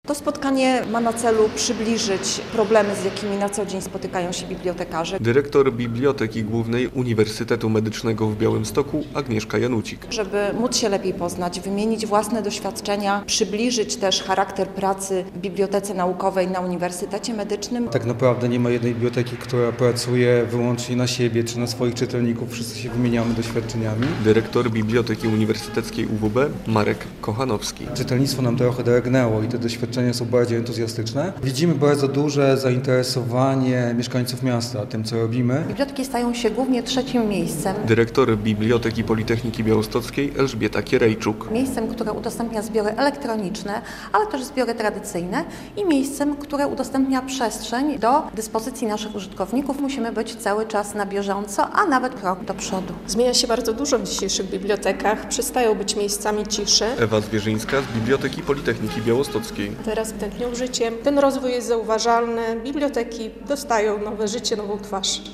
Podlaskie Forum Bibliotekarzy - relacja